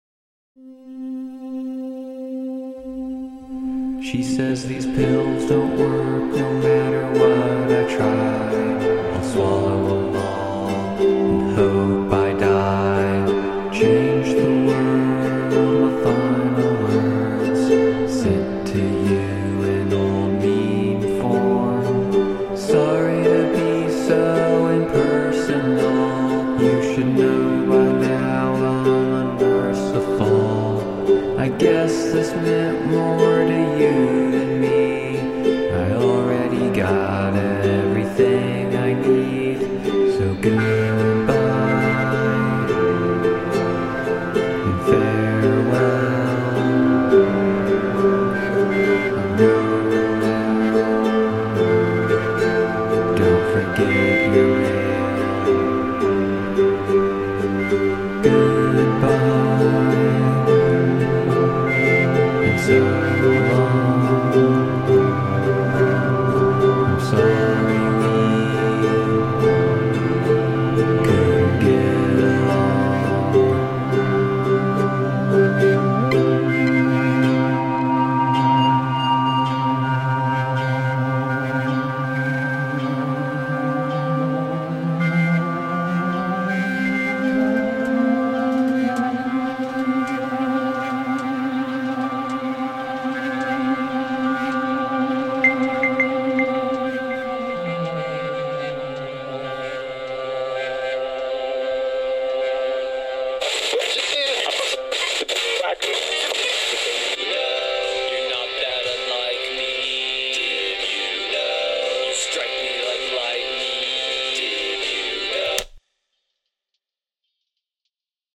Hello let me introduce myself with music experiments and beats. I am a heavy sample dude, and I will start out with 4 more sophisticated beats.